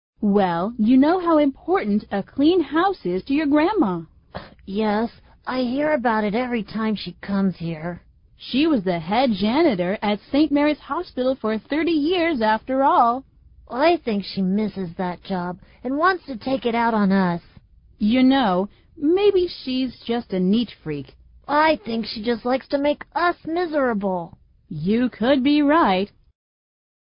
美语会话实录第263期(MP3+文本):A neat freak